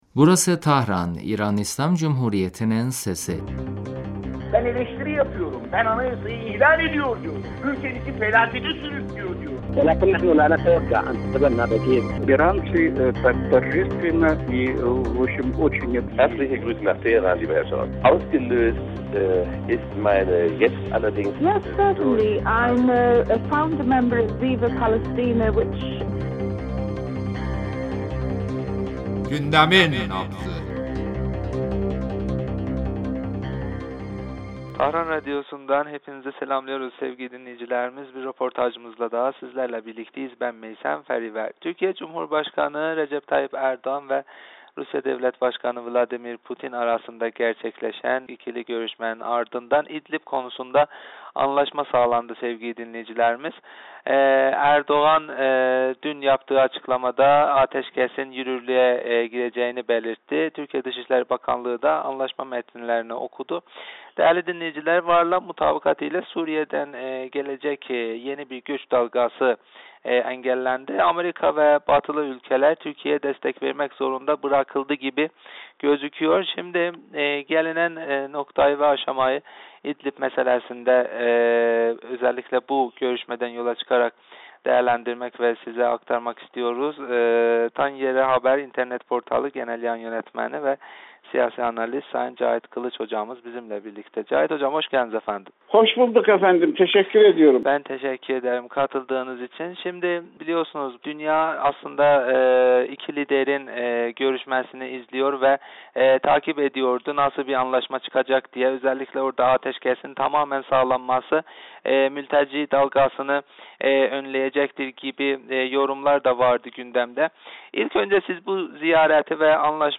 telefon görüşmesinde Rusya - Türkiye arasında İdlib konusunda sağlanan anlaşma üzerinde konuştuk.